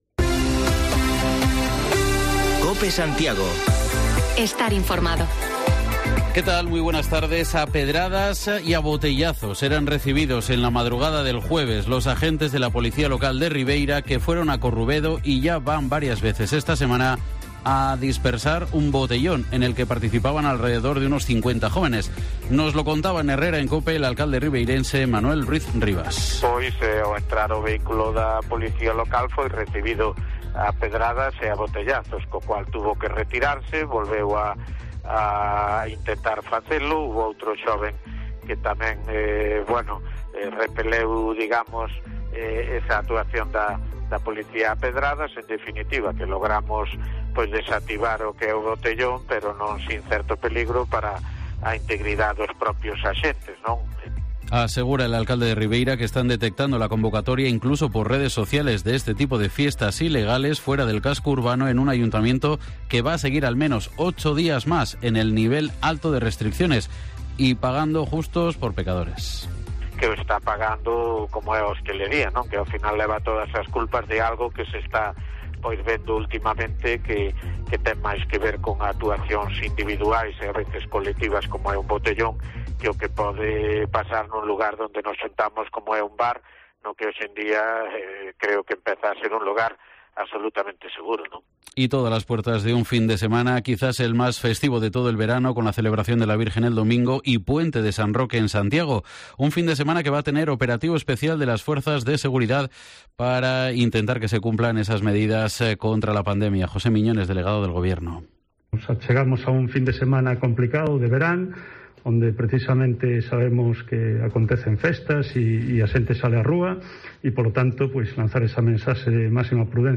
Informativo local Mediodía en Cope Santiago y de las Rías 13/08/2021